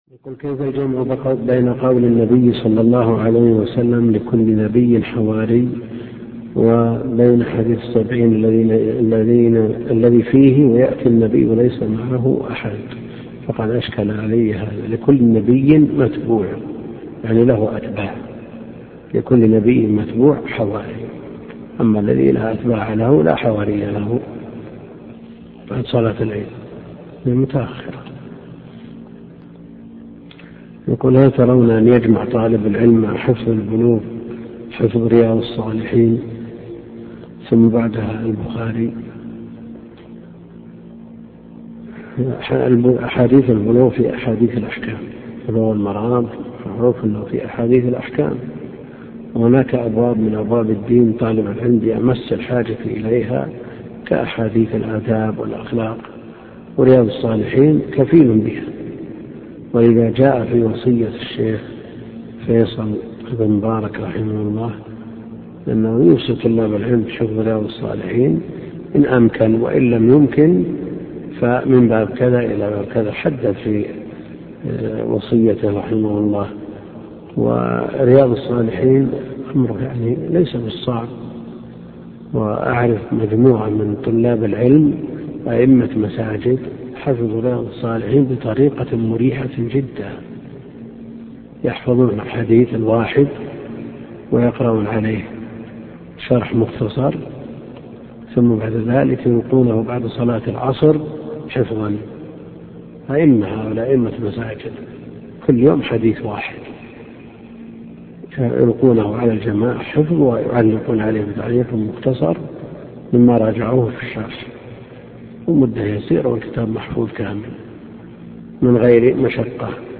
عنوان المادة الدرس (10) شرح سنن ابن ماجه تاريخ التحميل الأثنين 27 فبراير 2023 مـ حجم المادة 26.51 ميجا بايت عدد الزيارات 254 زيارة عدد مرات الحفظ 118 مرة إستماع المادة حفظ المادة اضف تعليقك أرسل لصديق